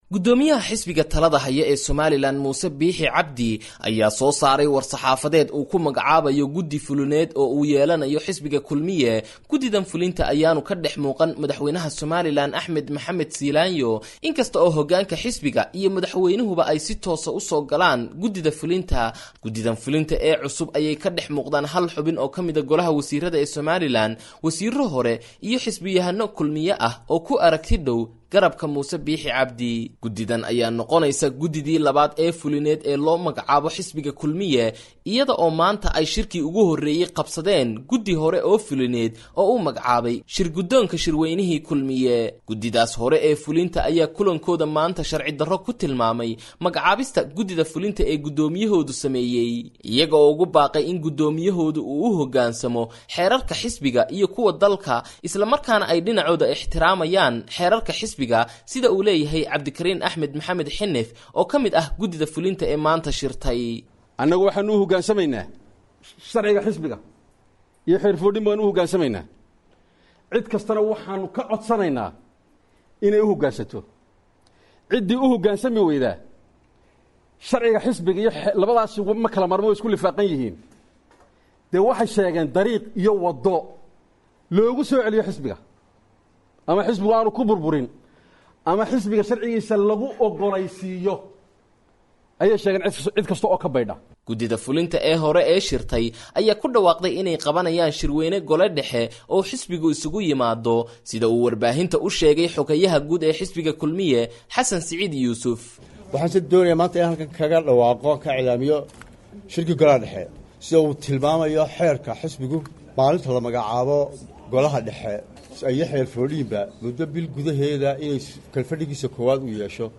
Warbixinta Khilaafka Kulmiye